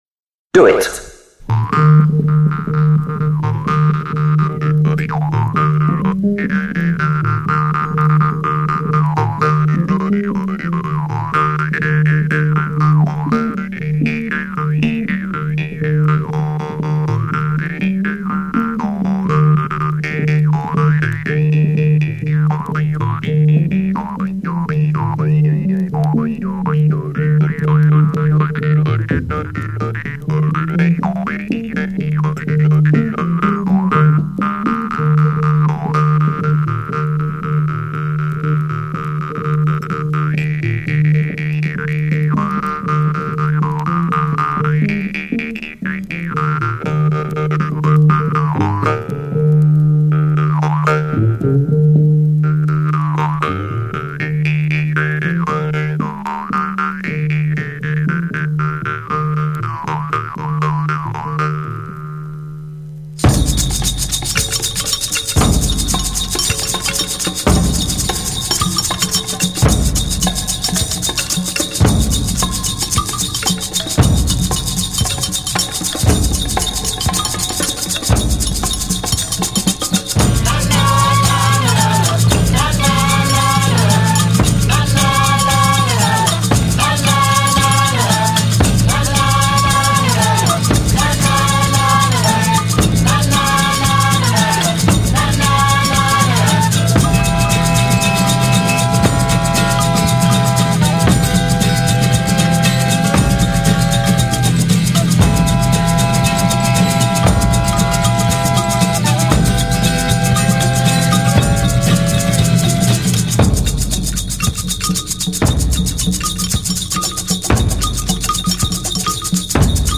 Disco/House Electronic Indie